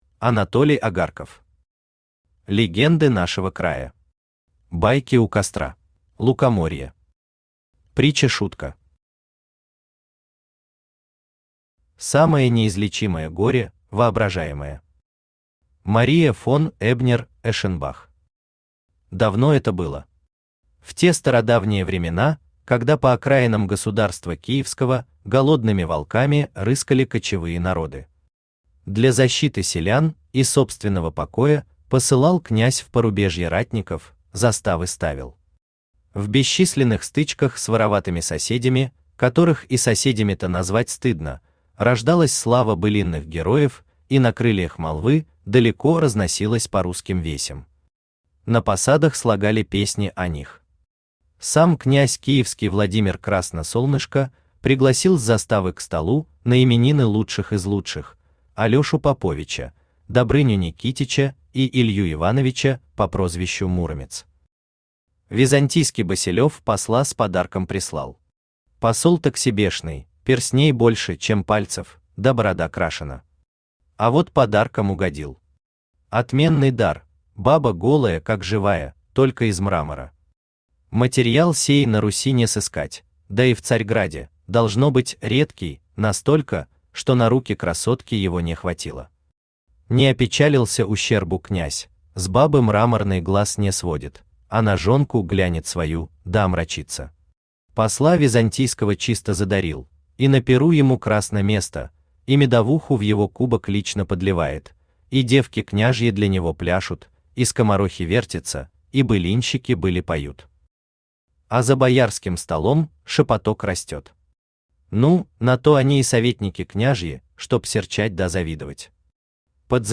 Аудиокнига Легенды нашего края. Байки у костра | Библиотека аудиокниг
Байки у костра Автор Анатолий Агарков Читает аудиокнигу Авточтец ЛитРес.